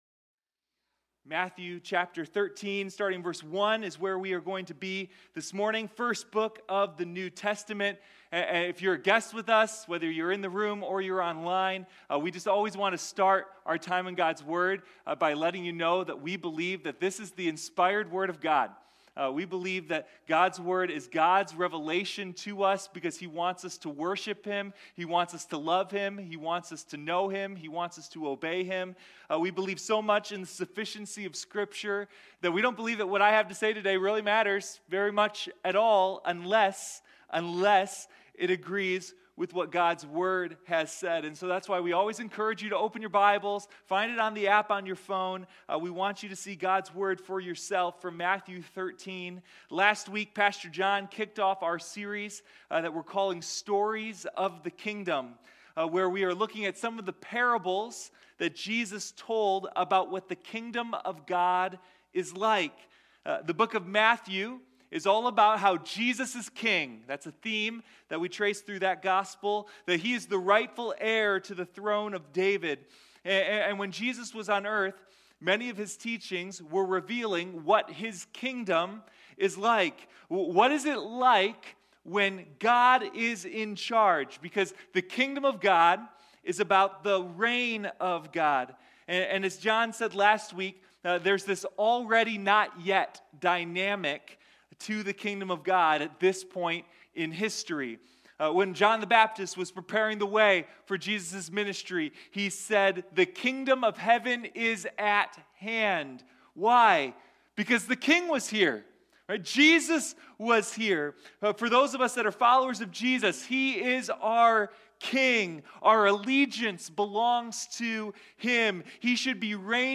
Sunday Morning Stories of the Kingdom